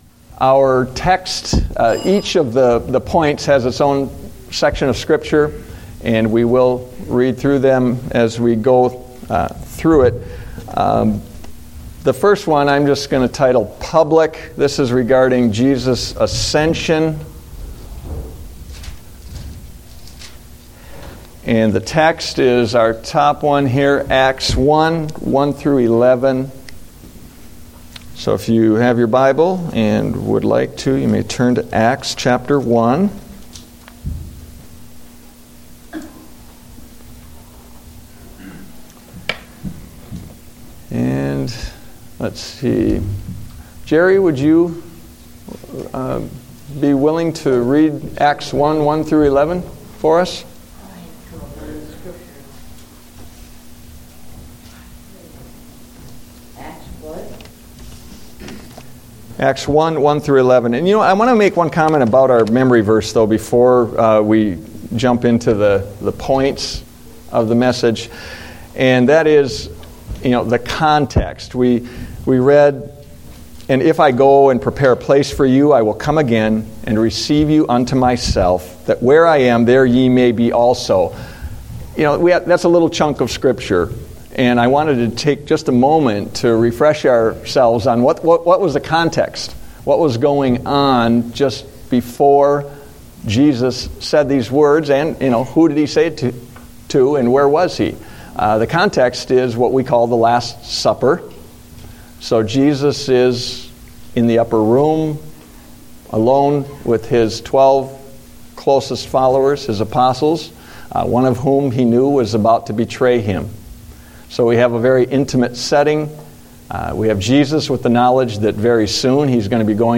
Date: December 28, 2014 (Adult Sunday School)